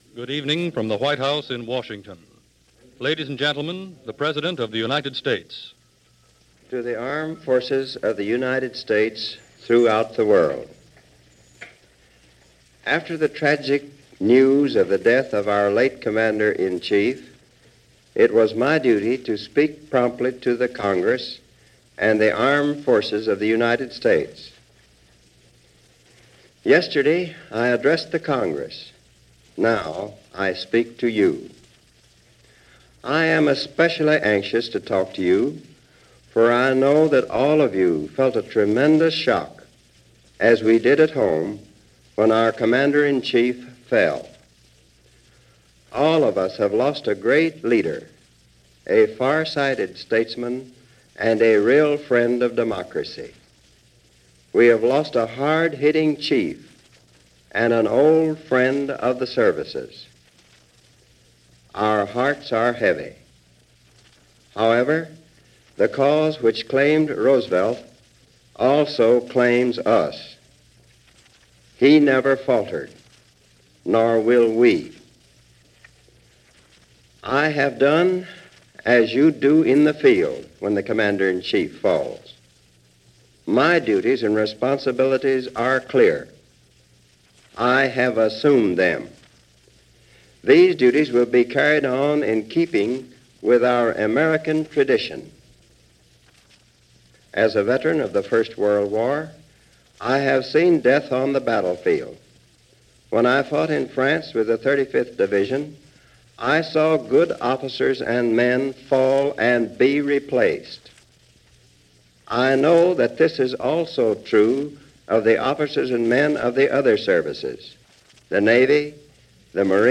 President Harry S. Truman - address to Armed Forces
April 17, 1945 – President Truman addresses the Armed Forces for the first time since the passing of President Roosevelt. It was broadcast via the Armed Forces Radio Service as well as all the radio networks throughout the U.S.:
Here is that broadcast by President Truman, as it was heard on April 17, 1945.